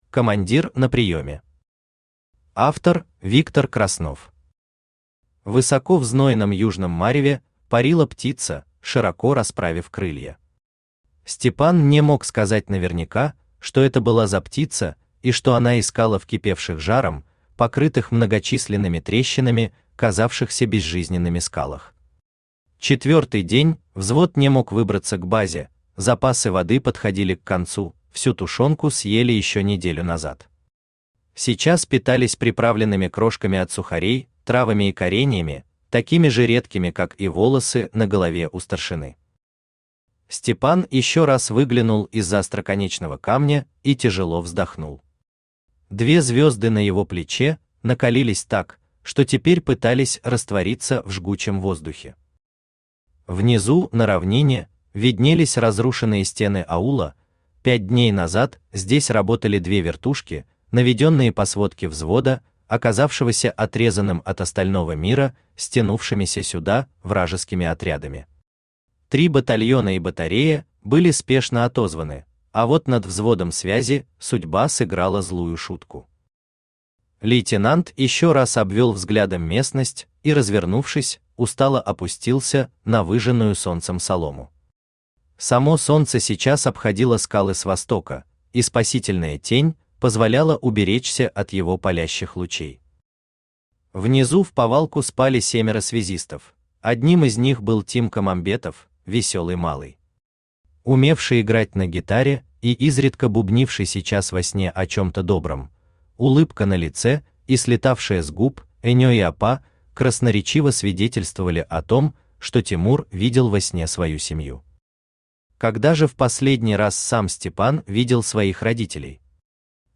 Аудиокнига Командир на приёме | Библиотека аудиокниг
Aудиокнига Командир на приёме Автор Виктор Краснов Читает аудиокнигу Авточтец ЛитРес.